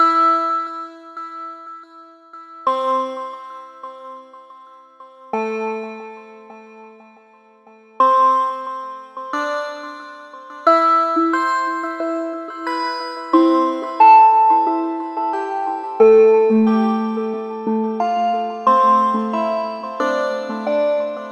标签： 恐怖 声景 冥王星 奇怪 舞蹈
声道立体声